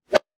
weapon_bullet_flyby_10.wav